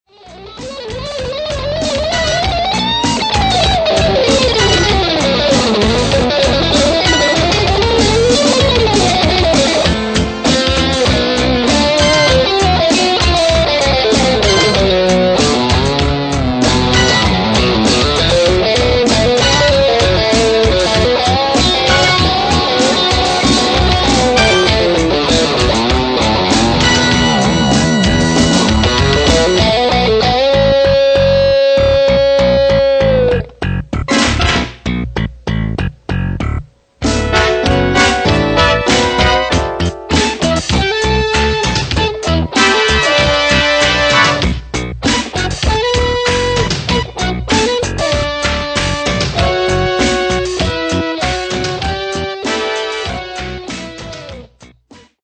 Guitar, keyboards, synth programming
Drums, percussion, drum sound programming